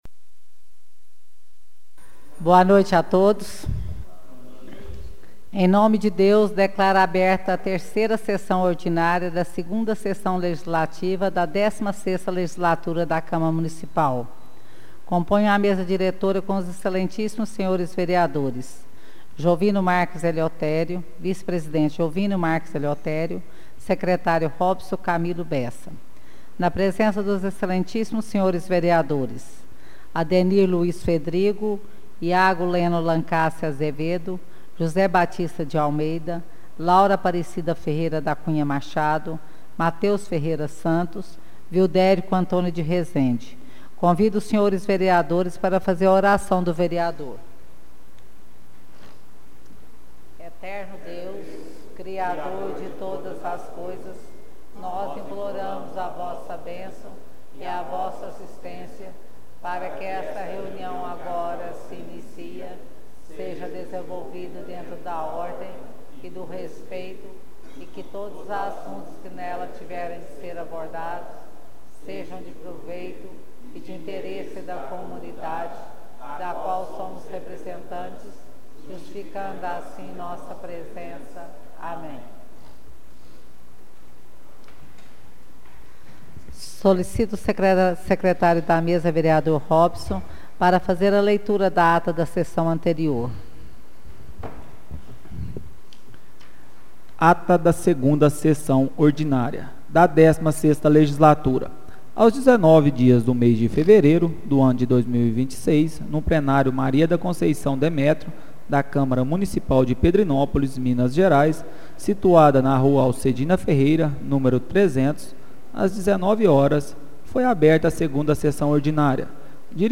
Áudio da 3ª Sessão Ordinária de 2026 — Câmara Municipal de Pedrinópolis